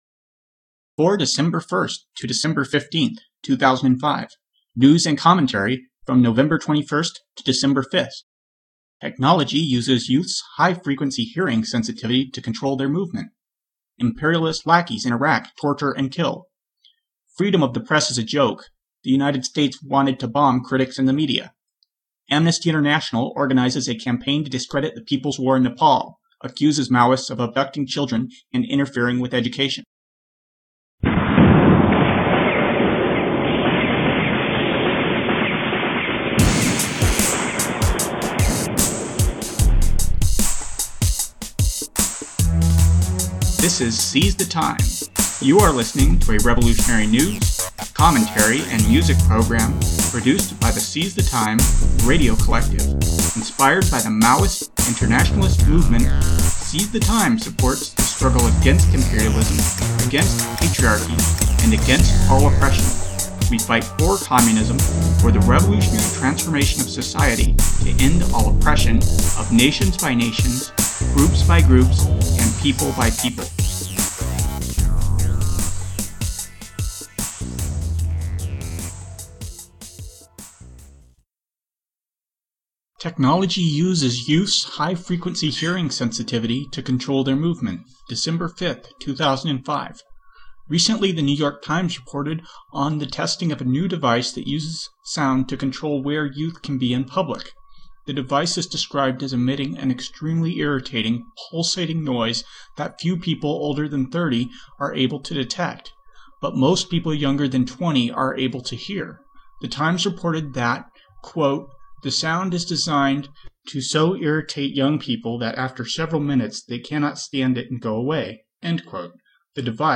Revolutionary news, commentary and music program produced by the Seize the Time Radio Collective.